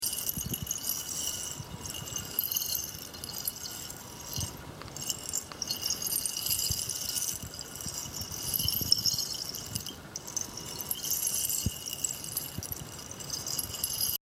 Ceramic Rain Stick
Click here to hear the ceramic rain stick.
rainstick.mp3